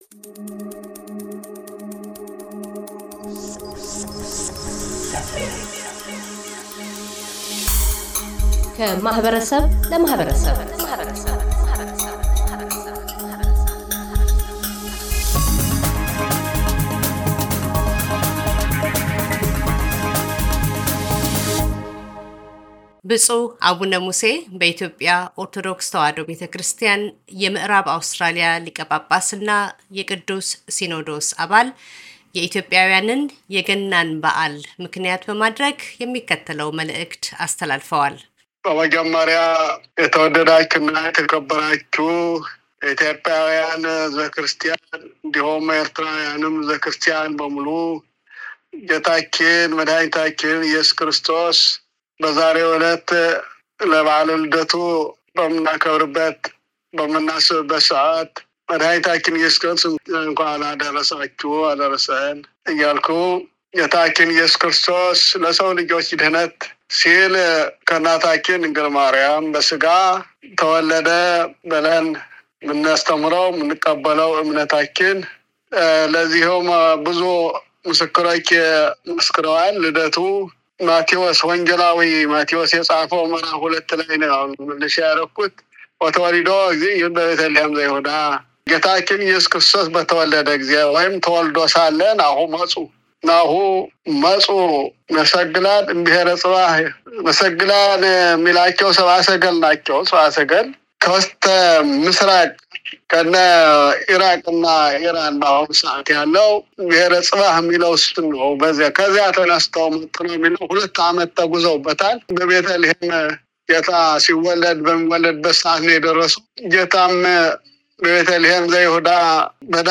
ብፁዕ አቡነ ሙሴ - በኢትዮጵያ ኦርቶዶክስ ተዋሕዶ ቤተክርስቲያን የምዕራብ አውስትራሊያ ሊቀ ጳጳስና የቅዱስ ሲኖዶስ አባል፤ የበዓለ ልደት መልዕክታቸውን ያስተላልፋሉ።